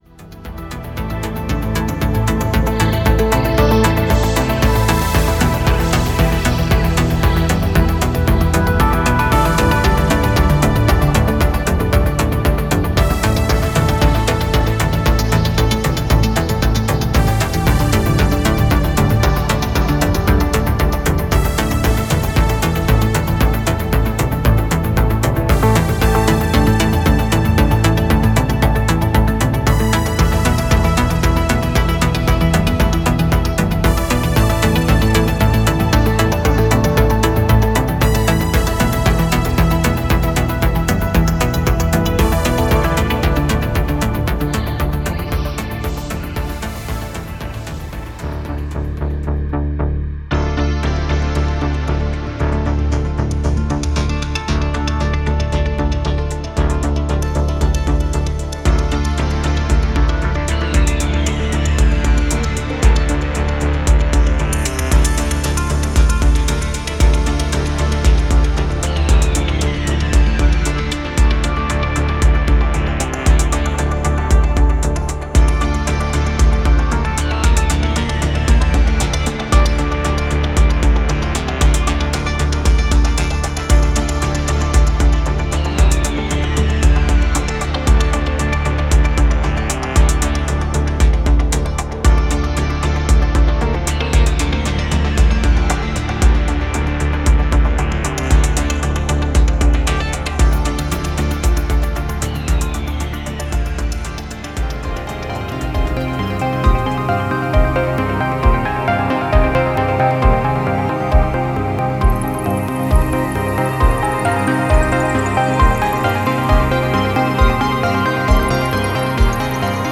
file under: Berliner Schule, NewAge, SpaceMusic